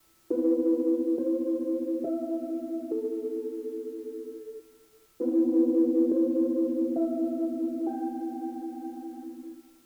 Threads of noise, tape loops, and samples.
I started a motif on the piano, which then made its way into a bunch of sample manglers / loopers, but at the end of the day I wasn’t happy with the results.